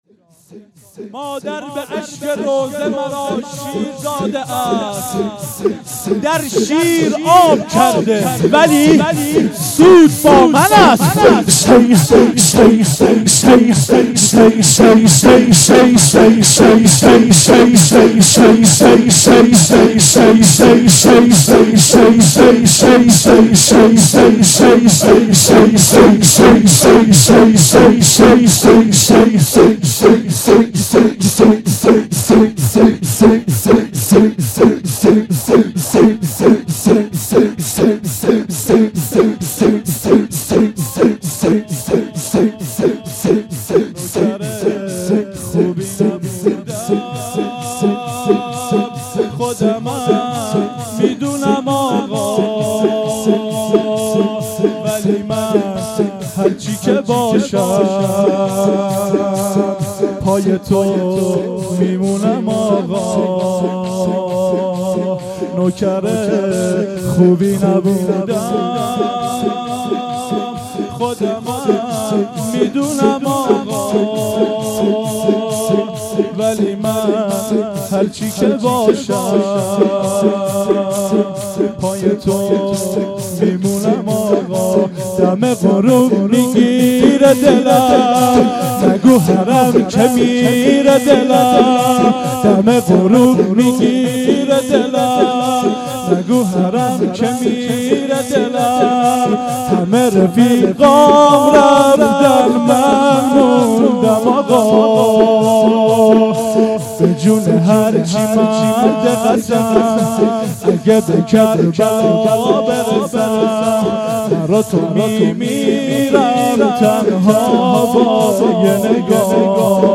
Shahadate-H.Masoumeh-93-Shoor1.mp3